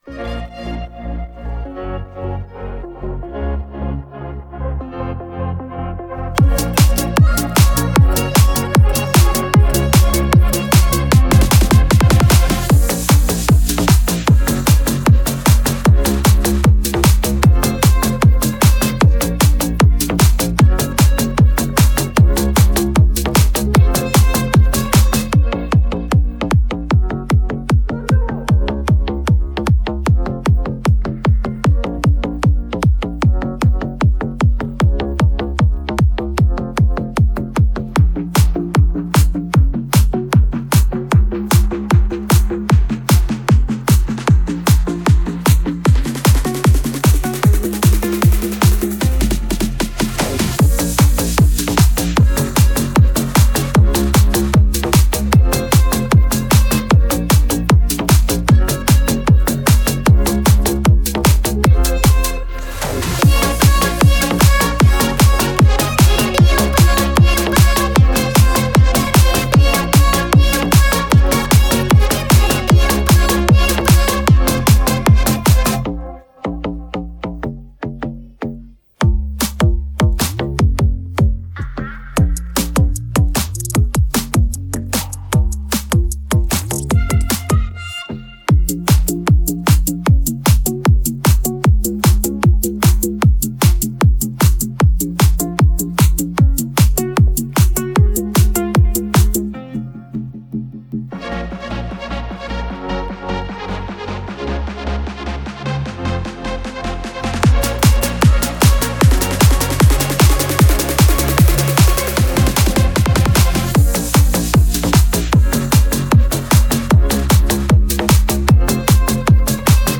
минус песни (караоке)